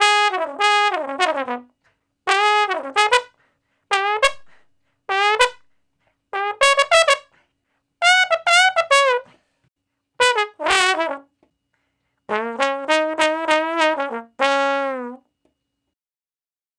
099 Bone Straight (Db) 04.wav